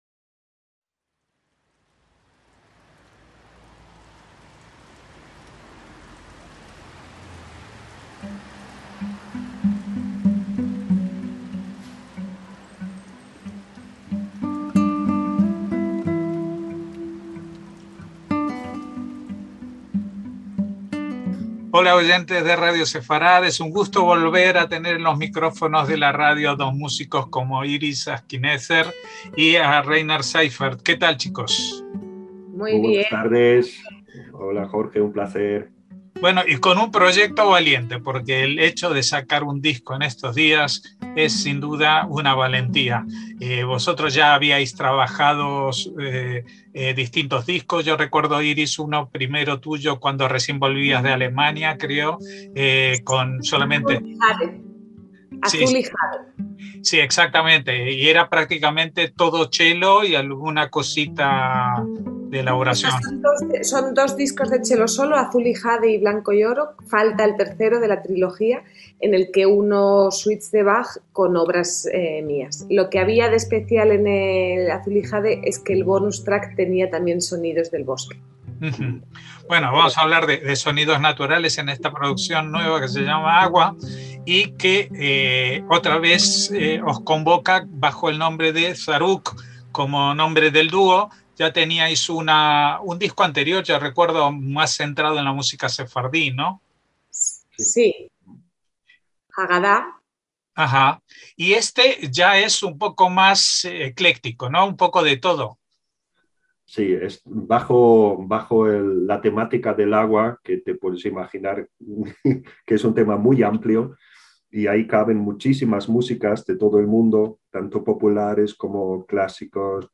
HABLA LA MÚSICA - En Radio Sefarad hemos hablado